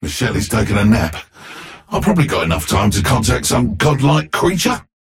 Bebop voice line - Miss Shelly is taking her nap.